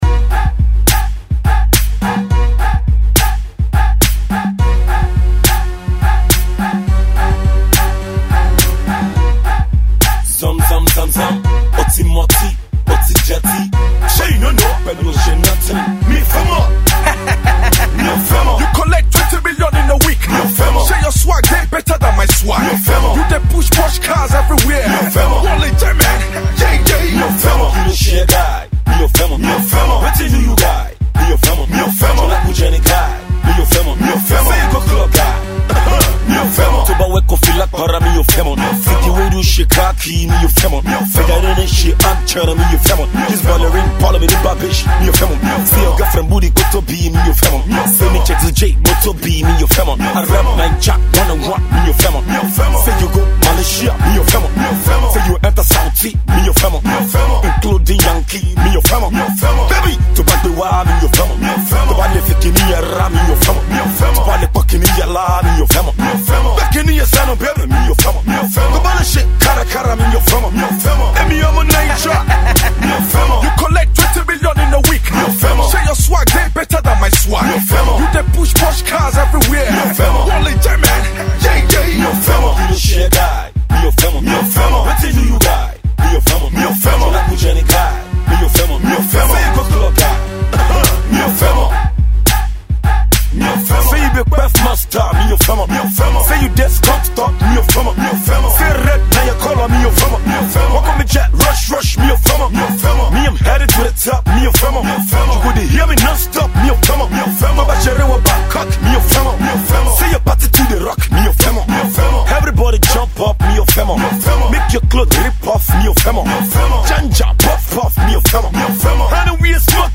Yoruba rap
Club Remix